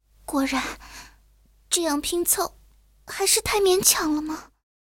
野牛被击毁语音.OGG